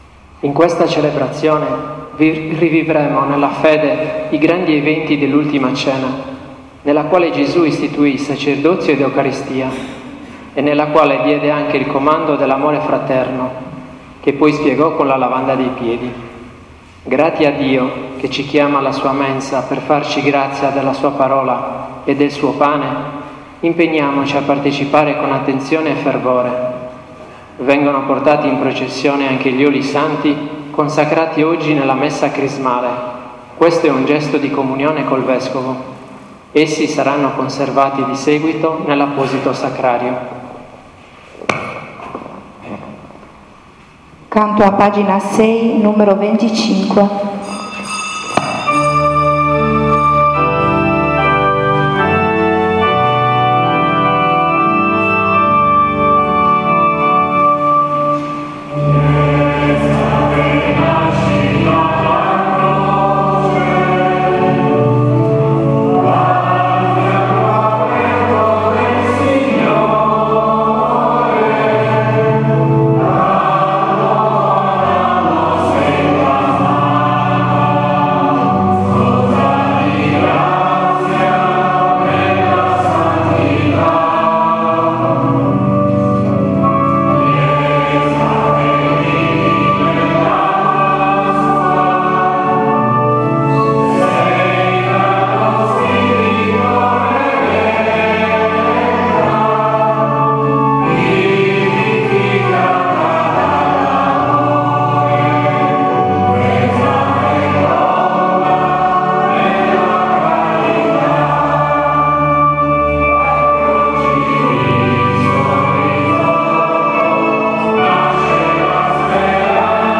13.04.2017 – S. MESSA “IN COENA DOMINI” NEL GIOVEDÌ SANTO E LAVANDA DEI PIEDI
S. Messa